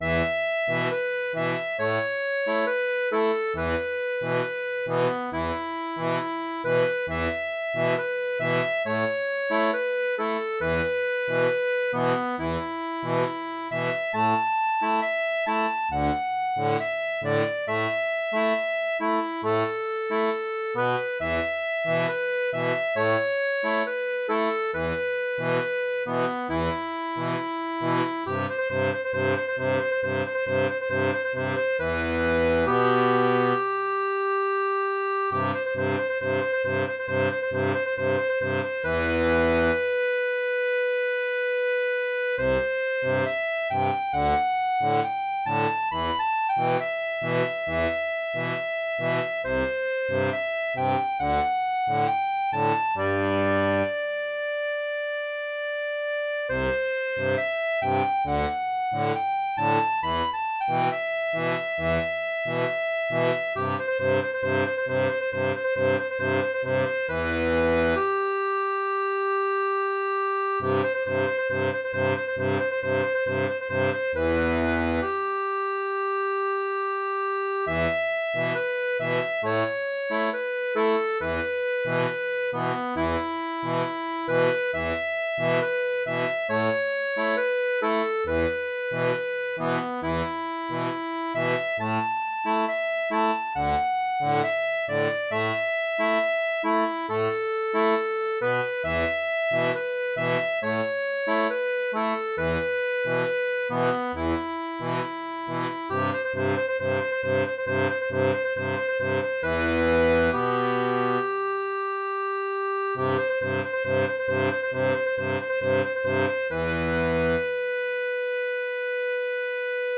• une version pour accordéon diatonique à 3 rangs
Pop-Rock